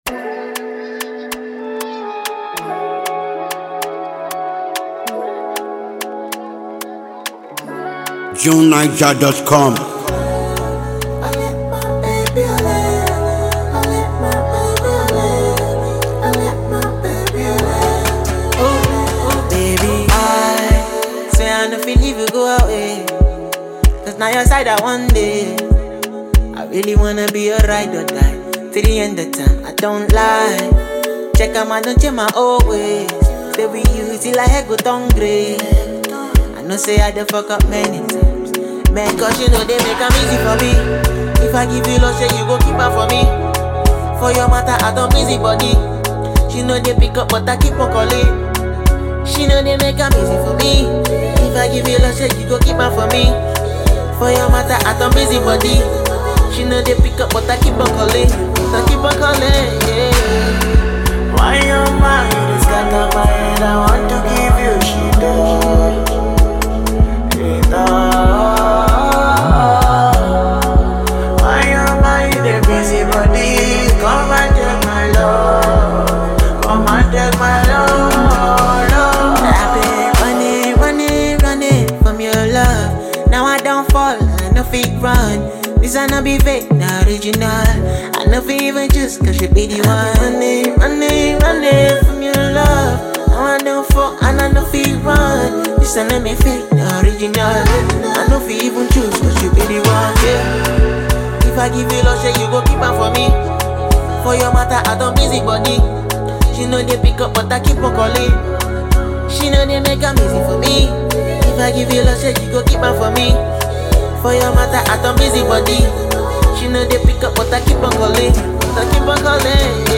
this intense and evocative track was from his most recent EP